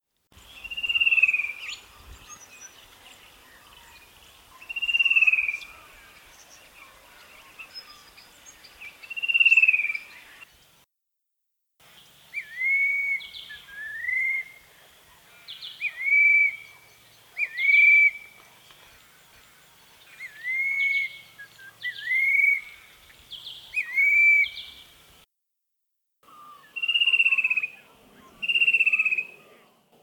Its distinctive features are the yellow eye-ring, barred tail, cinnamon colouring on its underparts and the male’s call, a mournful trill (click on the audio below).
Fan-tailed cuckoo calls:
fan-tailed-cuckoo1.mp3